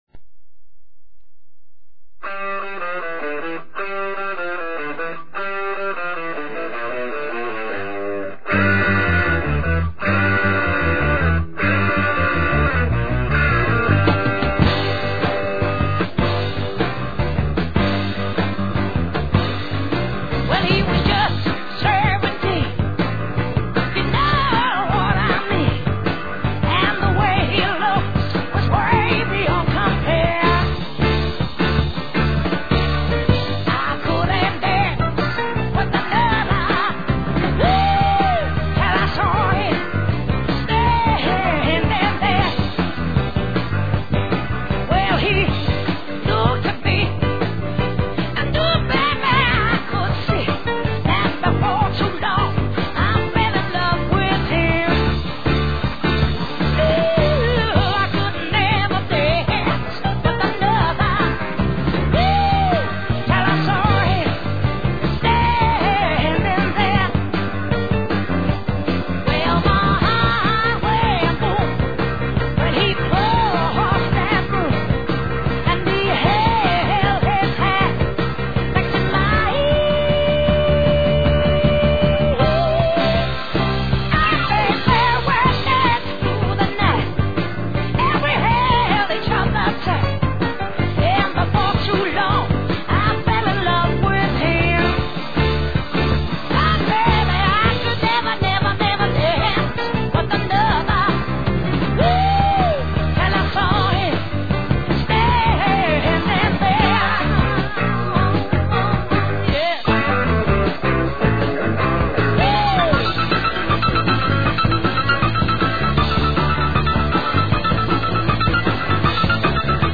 Mono and low quality sound but I hope you'll enjoy though.